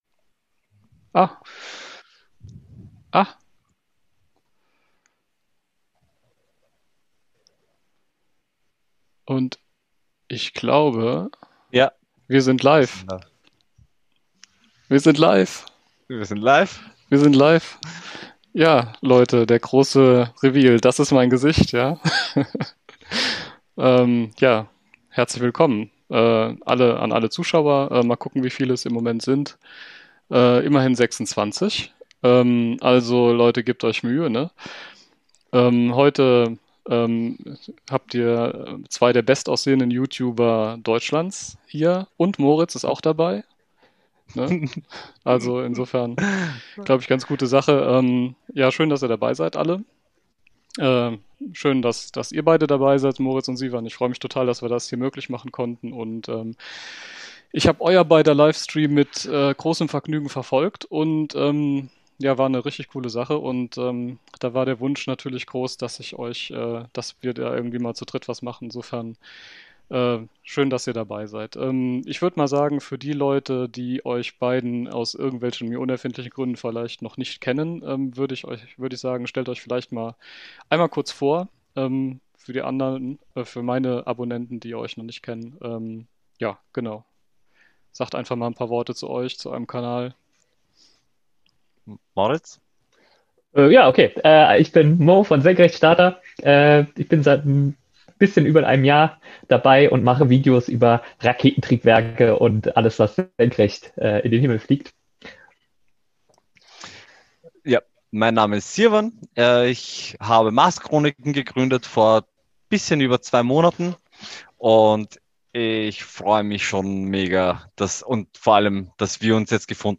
Wenn du den Livestream verpasst hast gibt es hier die Wiederholung.